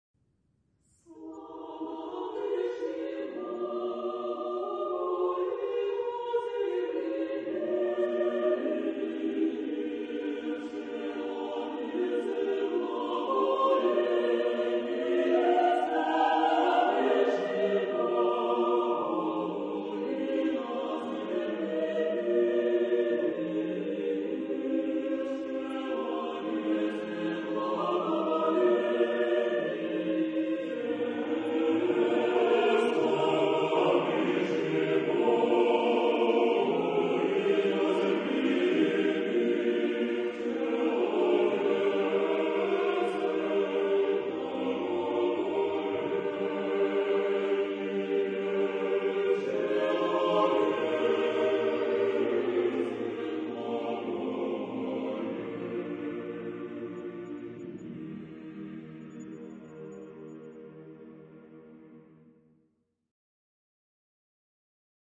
SATB (6 voices mixed) ; Choral score with piano for rehearsal only.
Orthodox liturgical hymn.
Genre-Style-Form: Sacred ; Orthodox liturgical hymn ; Orthodox song ; Psalm Mood of the piece: joyous ; majestic ; prayerful
Tonality: E flat major